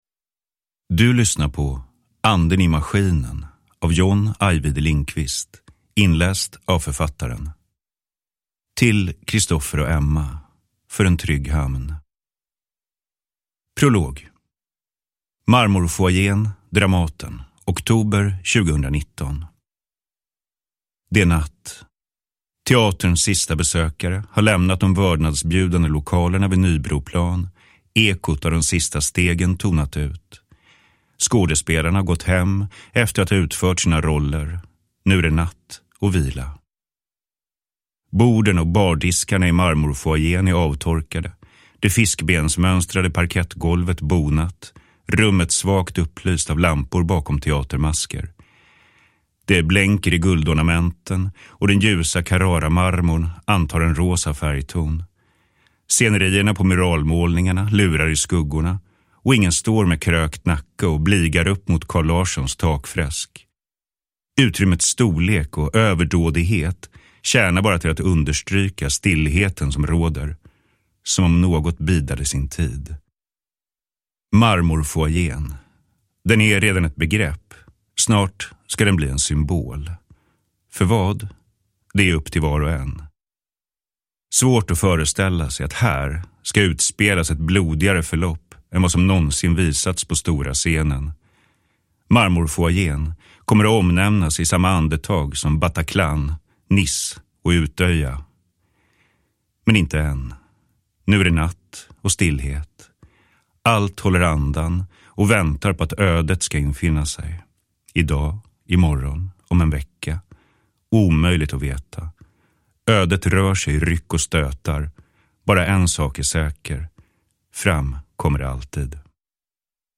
Uppläsare: John Ajvide Lindqvist
Ljudbok